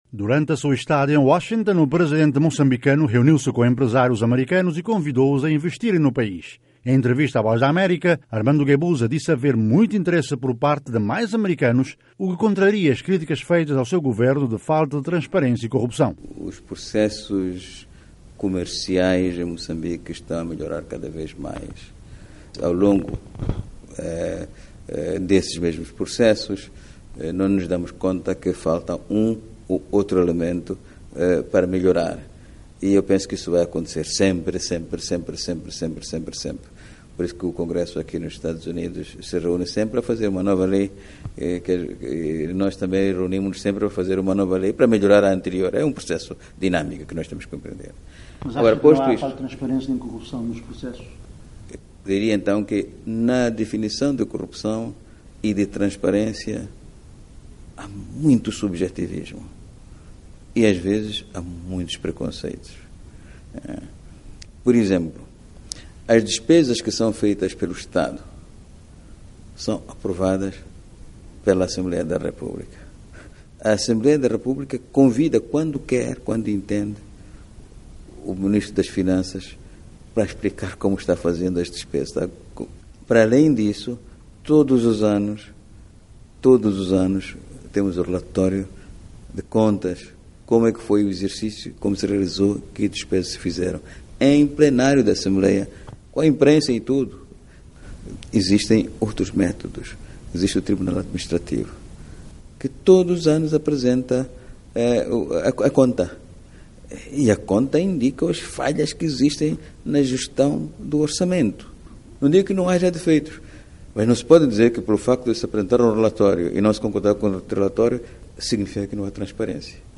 Entrevista à VOA em Washington.